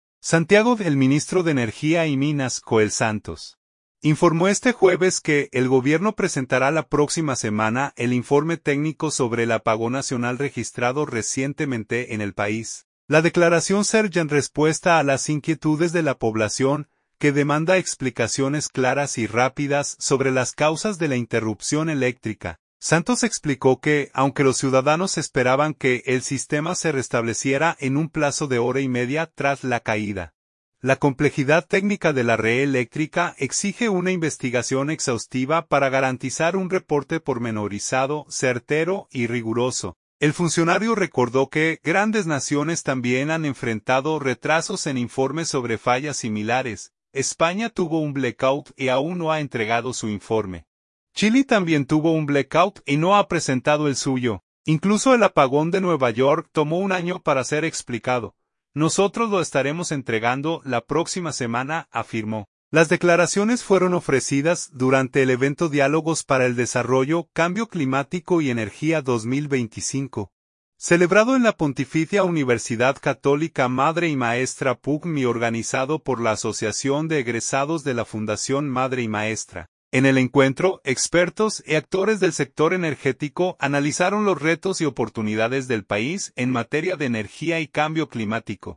Las declaraciones fueron ofrecidas durante el evento “Diálogos para el desarrollo: cambio climático y energía 2025”, celebrado en la Pontificia Universidad Católica Madre y Maestra (PUCMM) y organizado por la Asociación de Egresados de la Fundación Madre y Maestra.